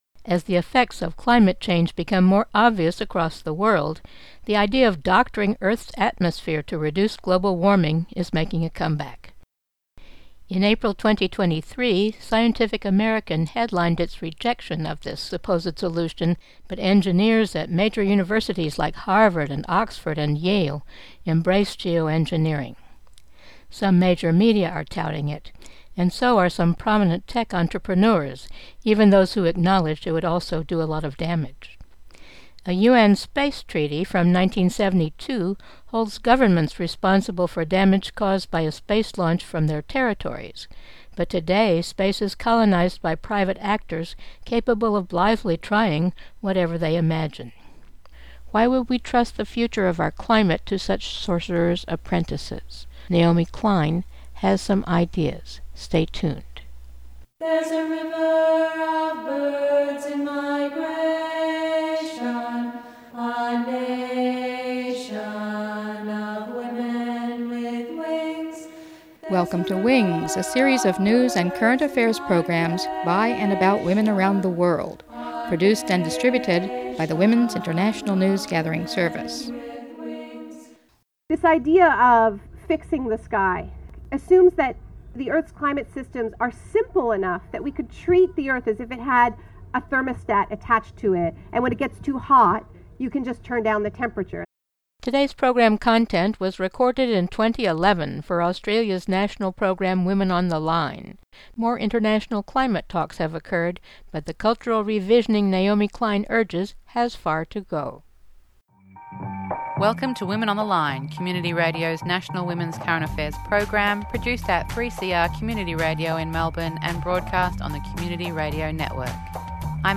Geo-engineering the atmosphere is still gaining fans as an allegedly quick and dirty way to reduce global warming. Naomi Klein, author of This Changes Everything: Capitalism vs. the Climate, addressed the World Social Forum in Dakar, Senegal, in 2011 about the hubris and the risks of geo-engineering – and how social movements must change the stories we tell each other about culture change and the complex systems of Earth.